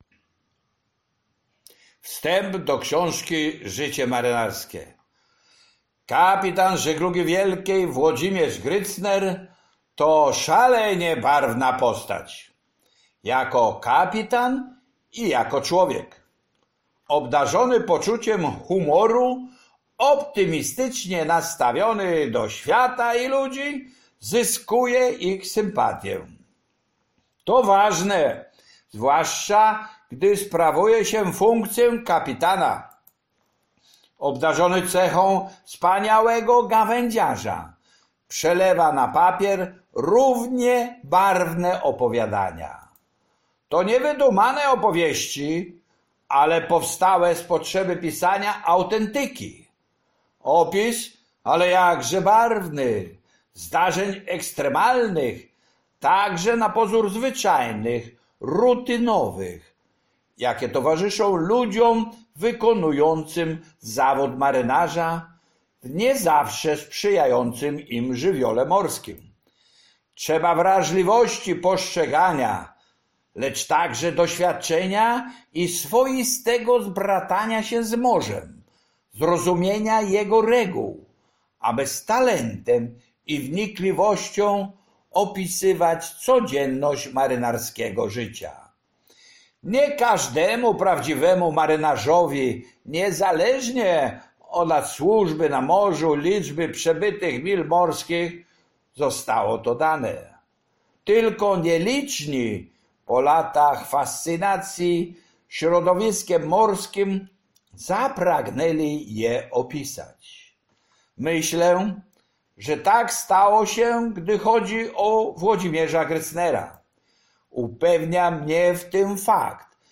Życie marynarskie (audiobook). Wstęp - Książnica Pomorska